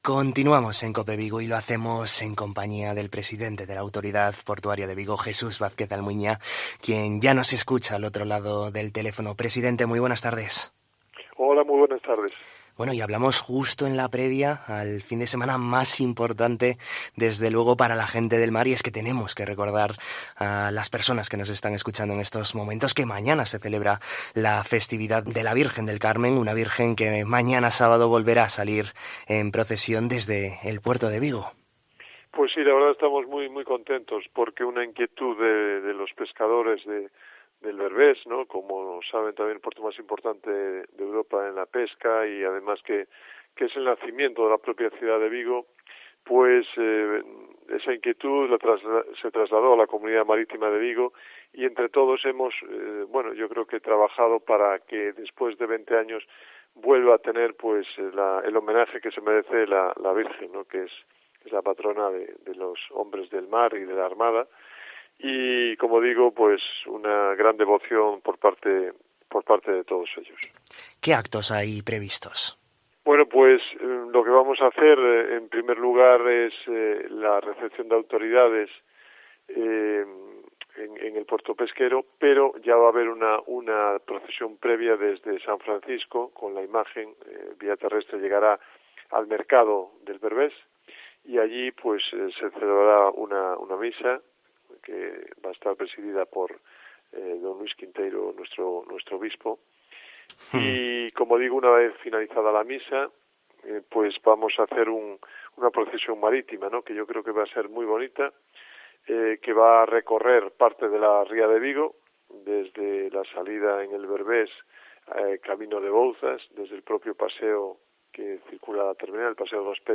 En COPE Vigo hablamos con el presidente de la Autoridad Portuaria de Vigo